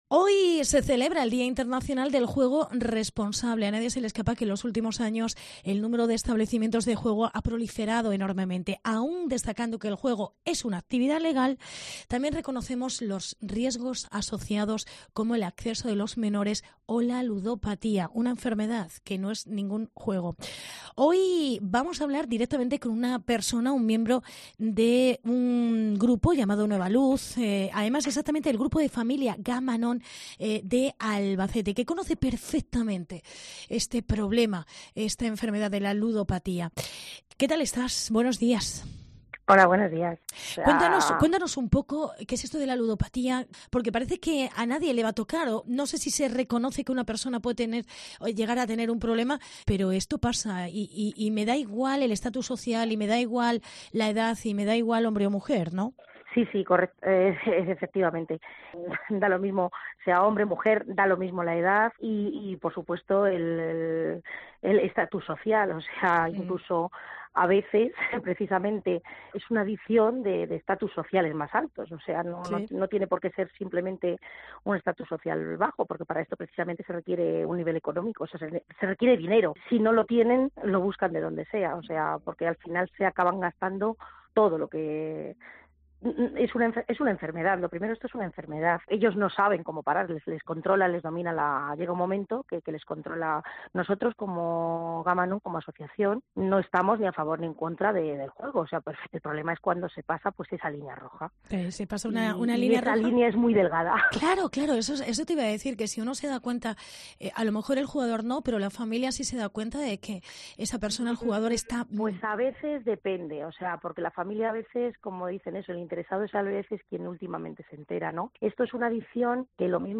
Entrevista con el Grupo Nueva Luz. Grupos de Familias GAM-ANOM